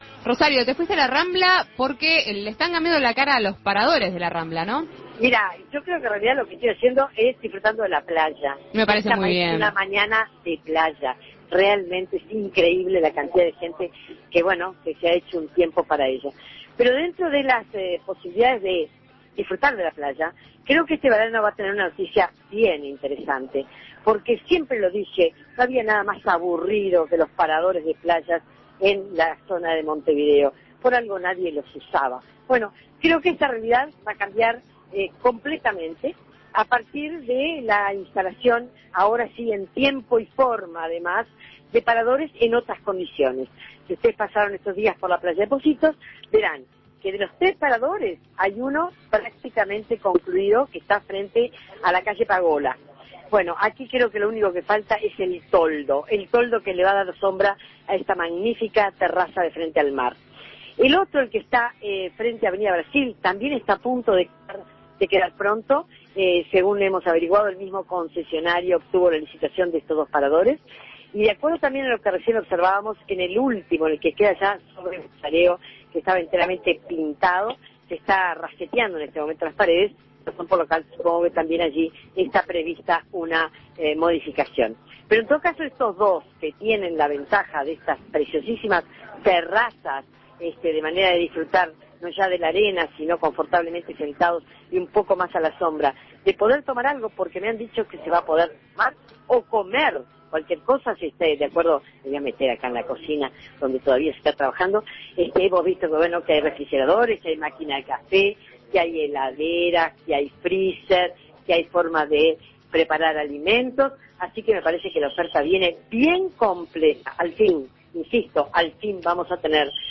Las mejoras que se realizan en los paradores y terrazas de la rambla de Montevideo comienzan a darle, además de otro aspecto, un servicio diferencial a una zona que será epicentro dentro de muy poco cuando la gente se vuelque a las playas. Móvil